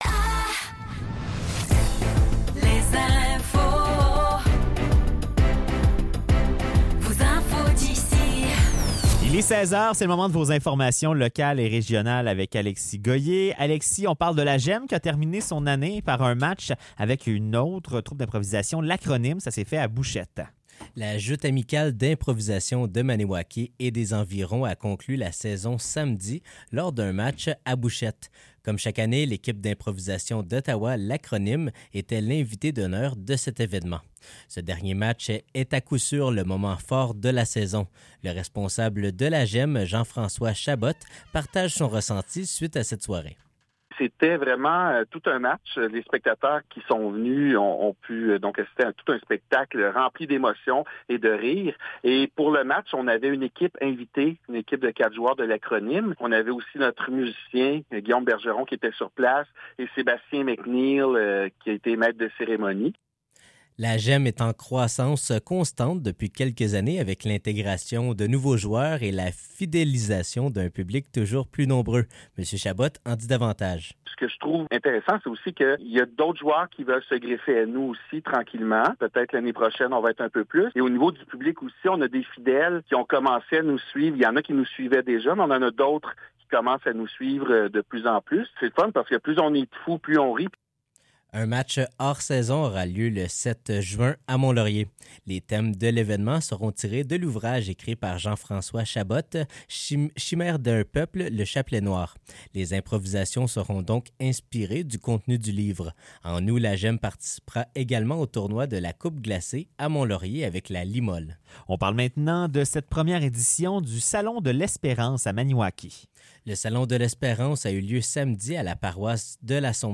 Nouvelles locales - 27 mai 2024 - 16 h